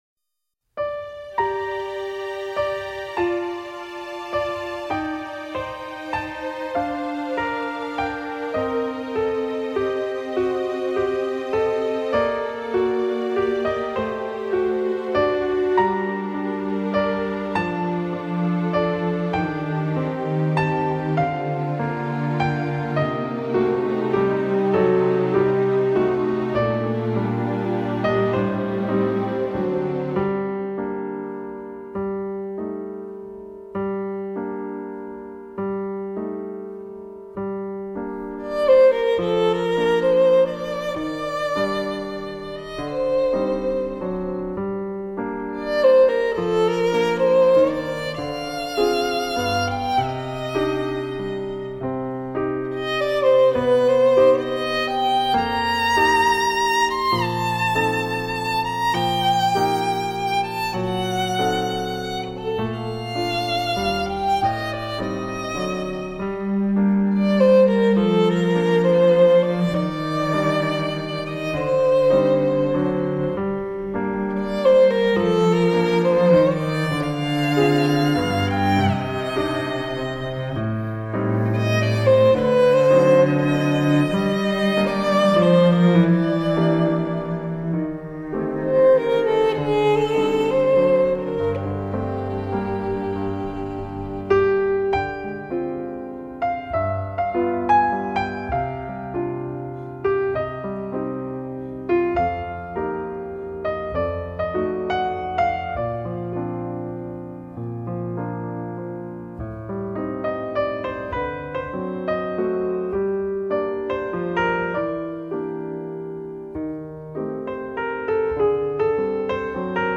简单的音符在钢琴中化为点点滴滴的流水轻轻敲打在我的心头。
微妙的着色，包括小提琴，大提琴和单簧管混合着，与钢琴合奏着一个纯净而绿色的背景世界。